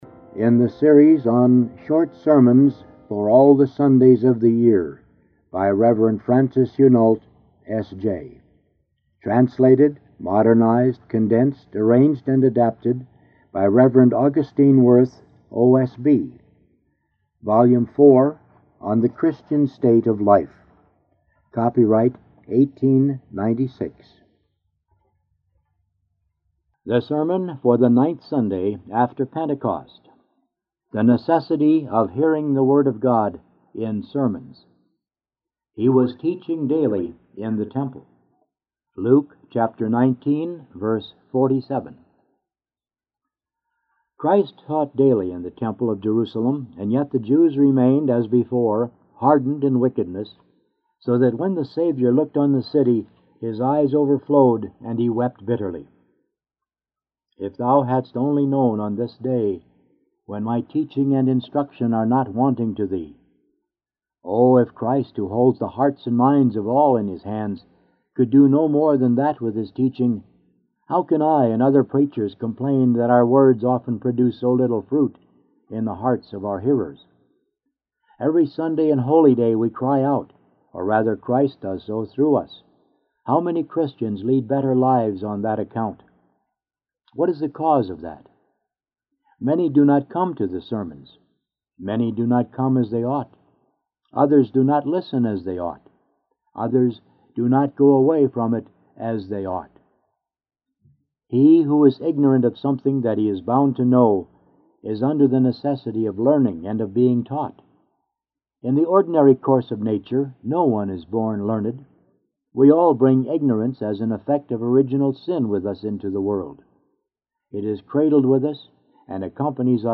Five Short Sermons
179p9-hearing-the-word-of-god-in-sermons.mp3